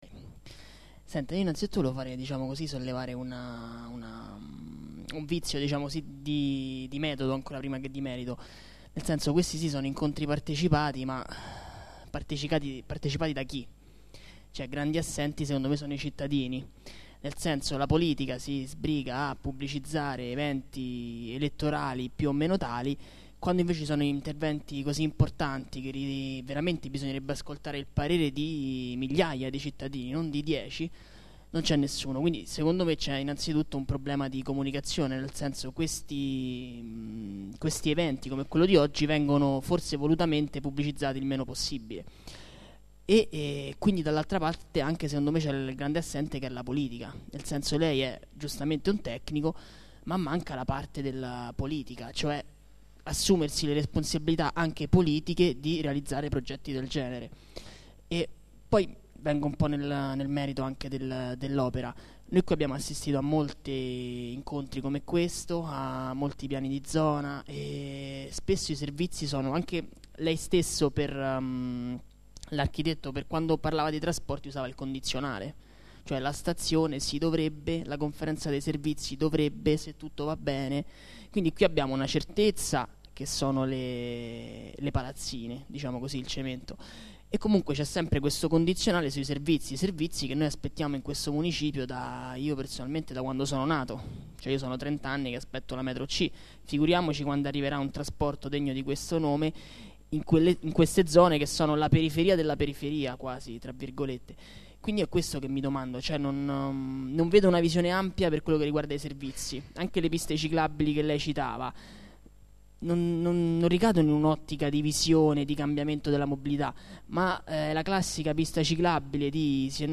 Assemblea
Registrazione integrale dell'incontro svoltosi il 4 aprile 2013 presso la sala consiliare del Municipio Roma VIII